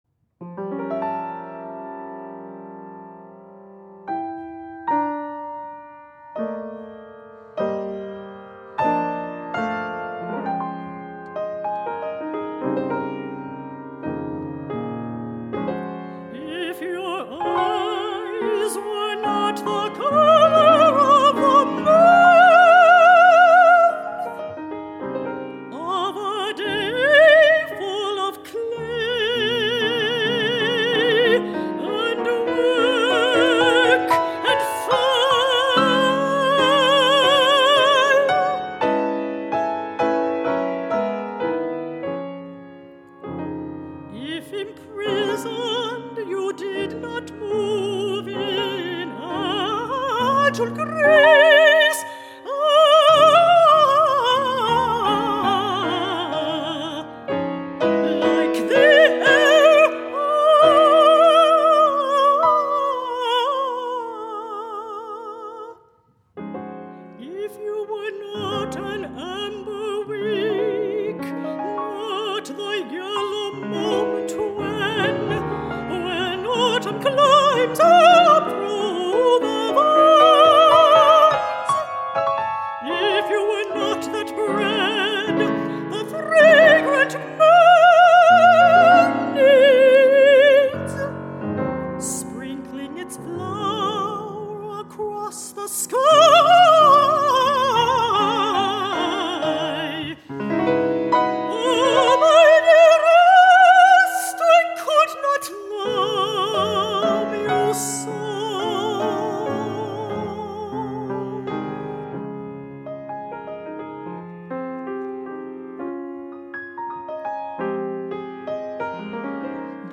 Soprano or Mezzo-Soprano & Piano (14′)